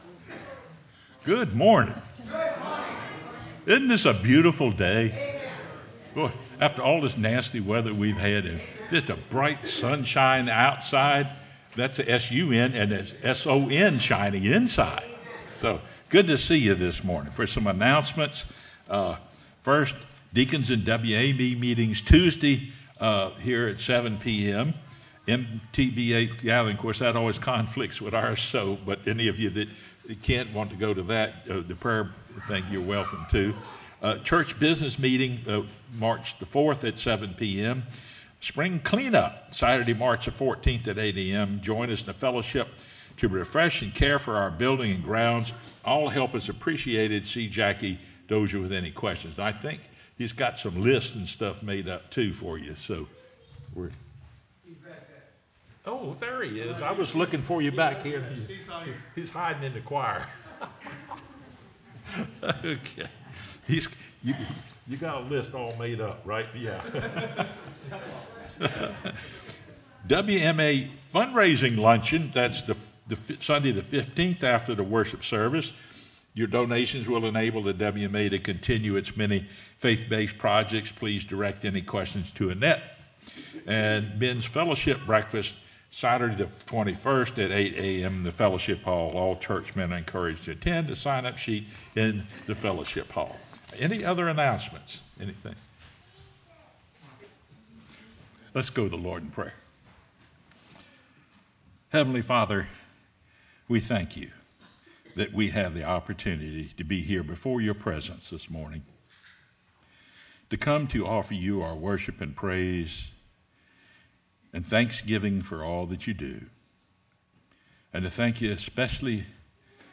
sermonMar01-CD.mp3